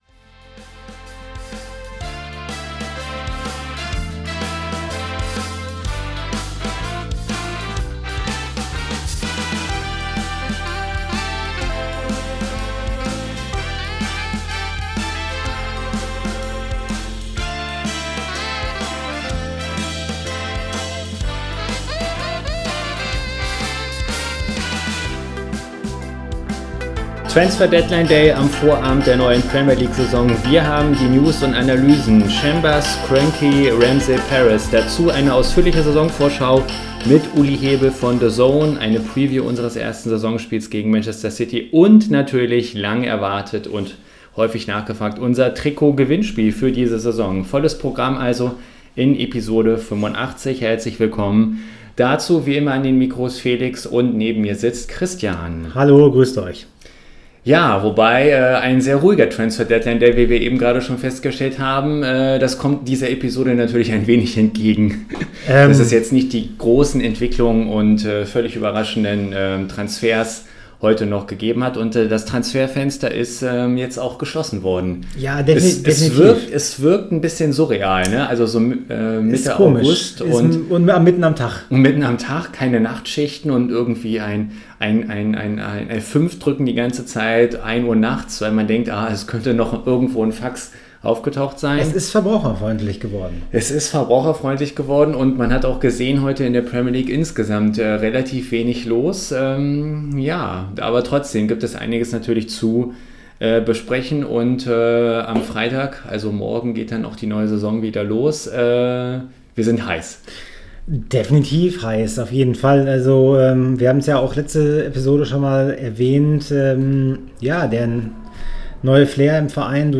Wie auch im letzten Jahr haben wir einen prominenten Arsenal-Blogger gebeten die Gewinnfrage einzusprechen.